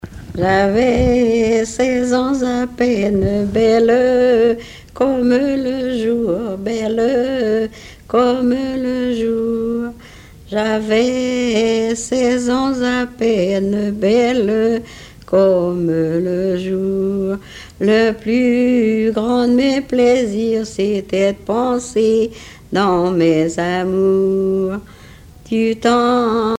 Genre strophique
Chansons traditionnelles